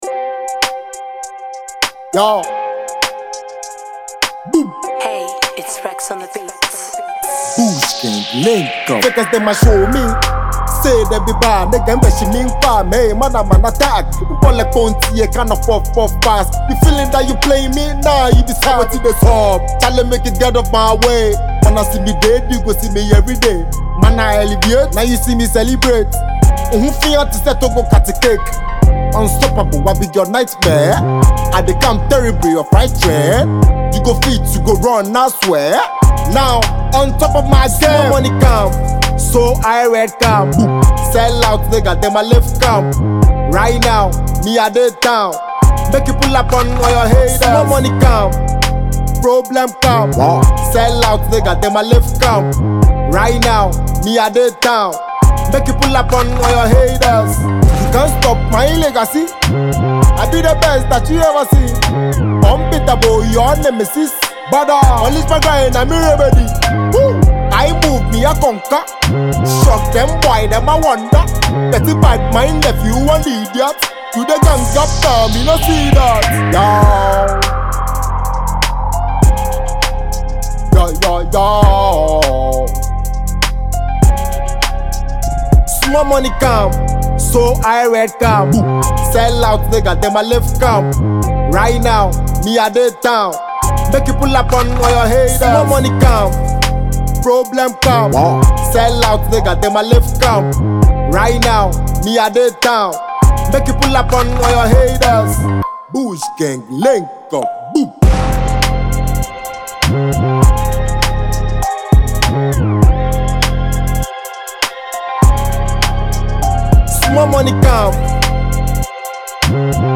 The catchy chorus